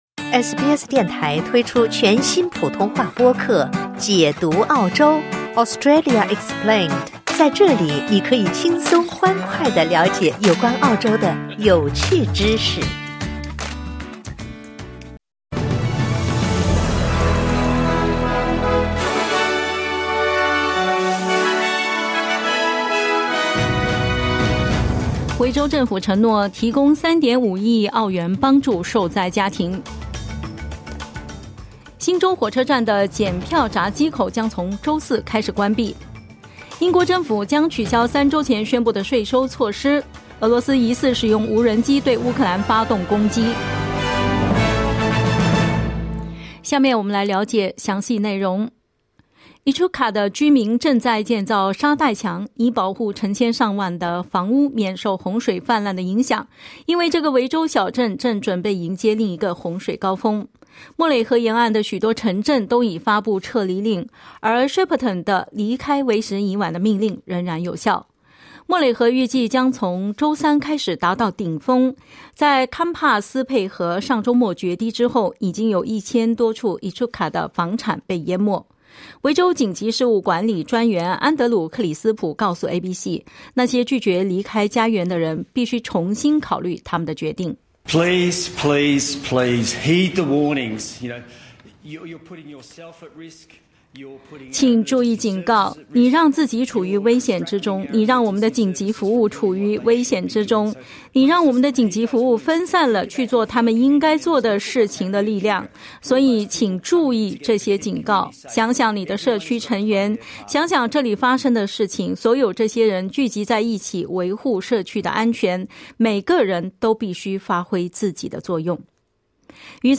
SBS早新闻（10月18日）
请点击收听SBS普通话为您带来的最新新闻内容。